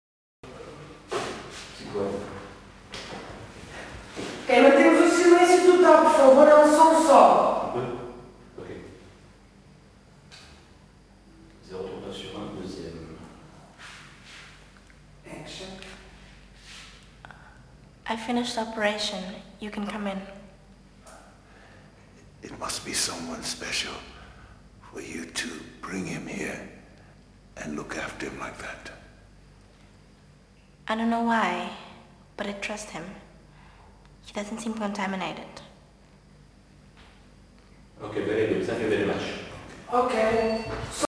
Le plan est large, les appareils ronronnent et soufflent, dans ce silence de mort même le sifflement de la RED s’entend.
La République des enfants – 039 / 1 w2 – perche au centre
En prenant ce parti pris de résonance sur fond de souffles d’appareils, la scène acquiert un sorte de gravité et de confidentialité très prenante parce que cela incite à tendre plus l’oreille.